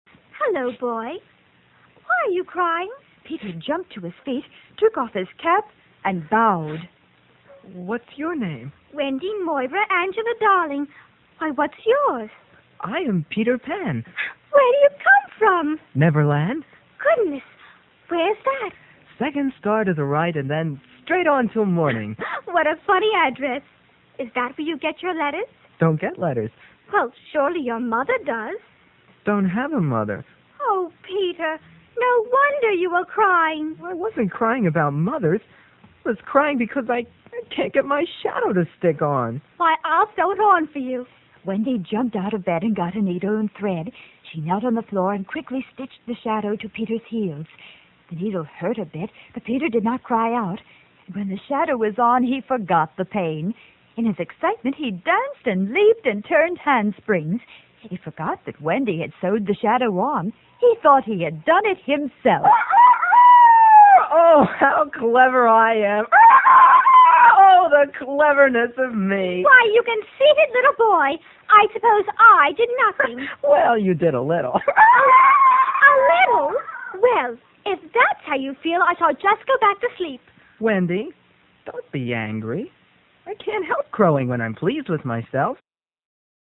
These 15-minute shows were taped about three weeks in advance in Studio A and were aired each weekday at 9:45am on WFIL.
The shows were basically live-to-tape.
Someone rocked back and forth in a creaky old chair for the boat, and the crocodile was an alarm clock and mike covered over with that old metal wash-tub from the sound effects cabinet.